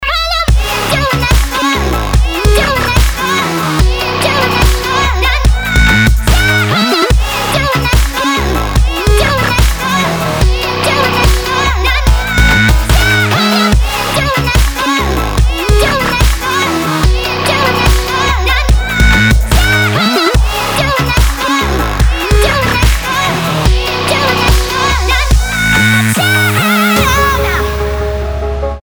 DubStep / Дабстеп